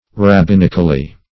Search Result for " rabbinically" : The Collaborative International Dictionary of English v.0.48: Rabbinically \Rab*bin"ic*al*ly\, adv.